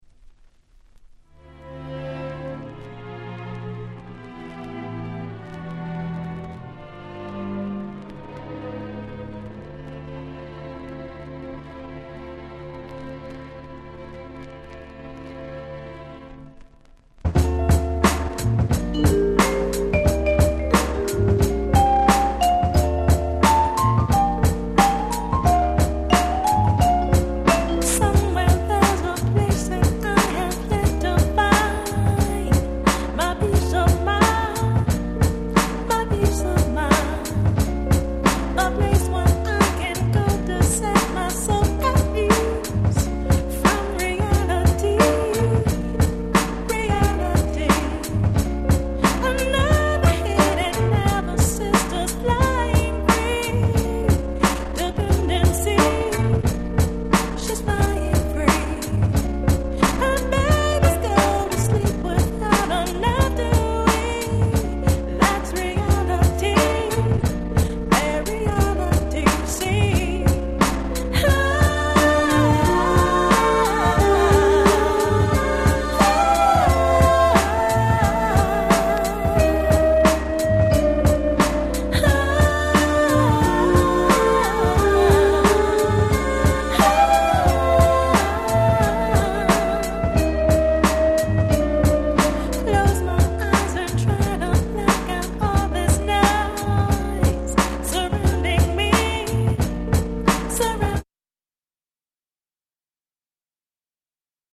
Nice Hip Hop Soul♪
90's R&B